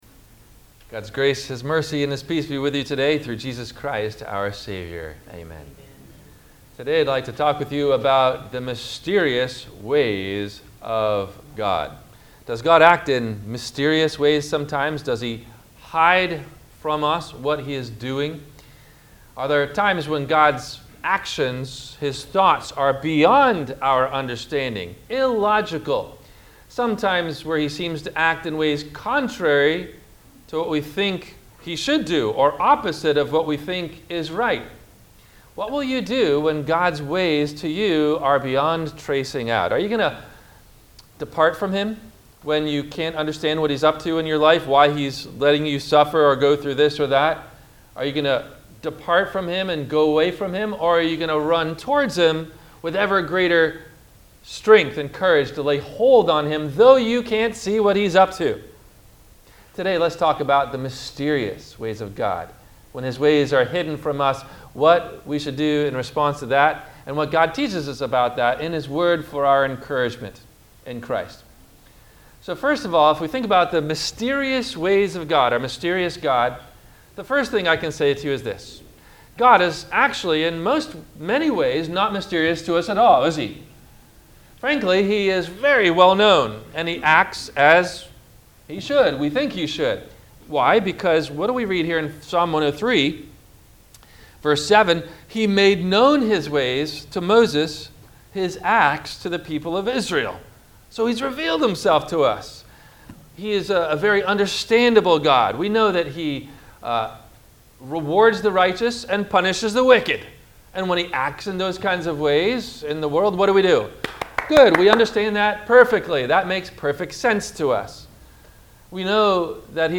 - Sermon - July 18, 2021 - Christ Lutheran Cape Canaveral